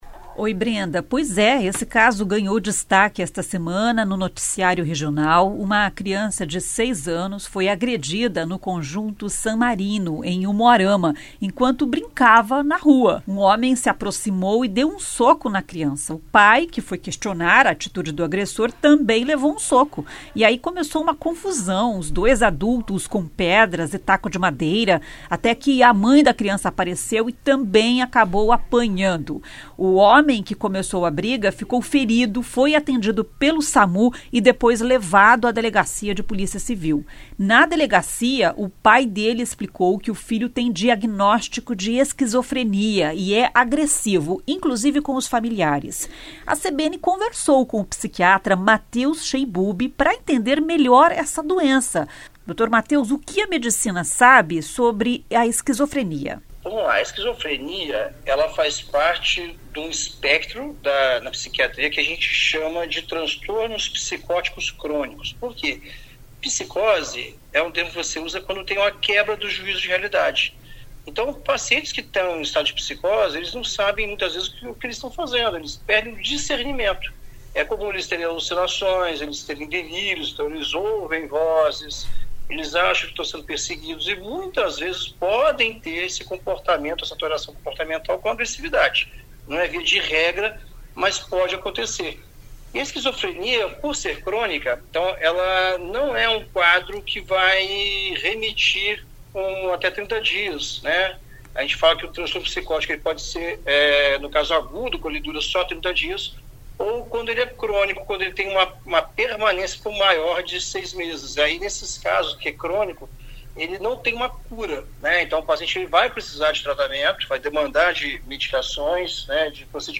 Segundo especialista ouvido pela CBN, casos de pacientes agressivos são raros. O importante é que o esquizofrênico não abandone o tratamento.